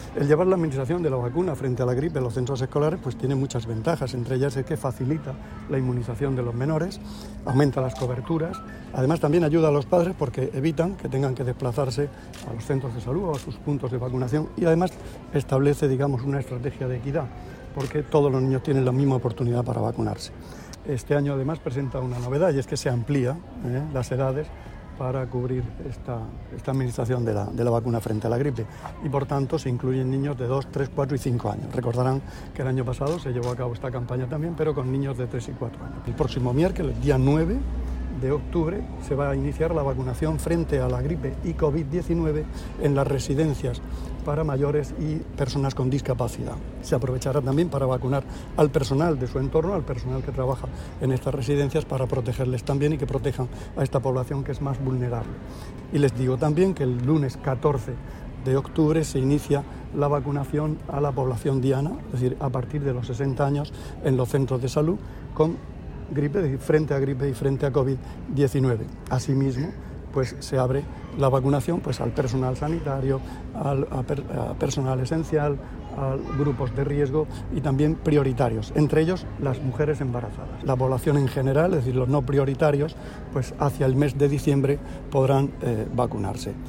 Sonido/ Declaraciones del consejero de Salud, Juan José Pedreño [mp3], sobre la campaña de vacunación de gripe de este año.